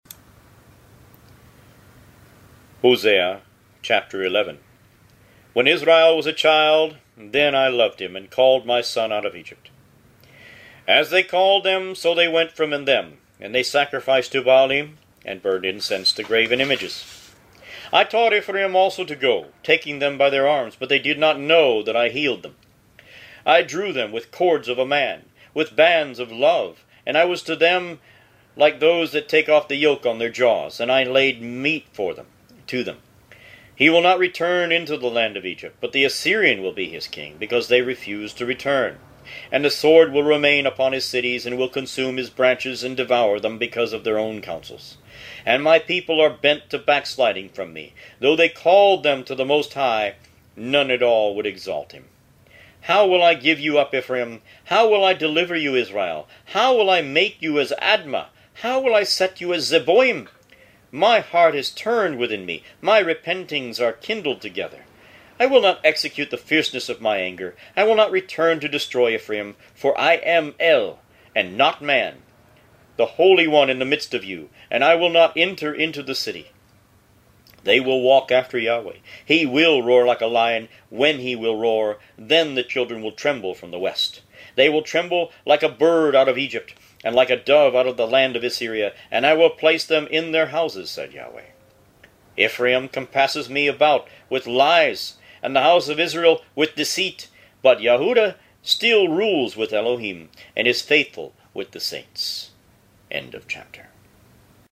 Root > BOOKS > Biblical (Books) > Audio Bibles > Tanakh - Jewish Bible - Audiobook > 28 Hosea